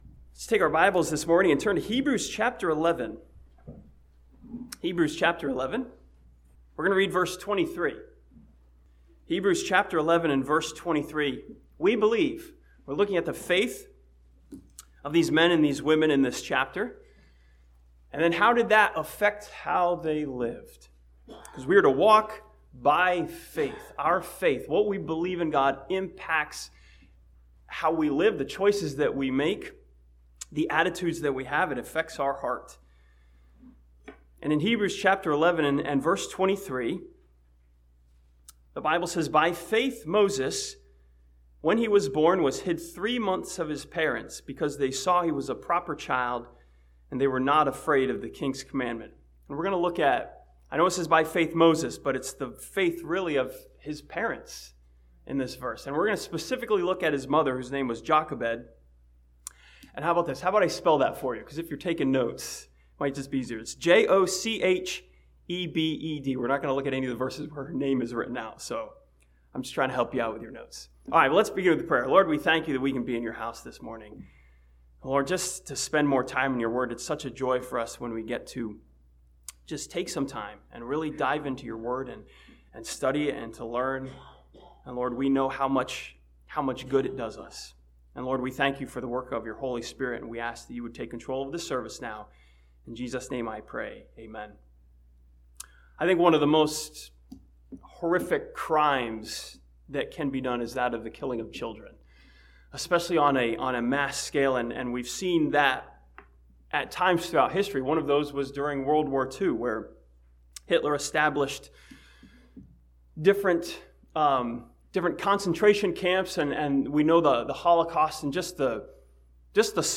This sermon from Hebrews chapter 11 studies the birth of Moses and the faith of his mother Jochebed.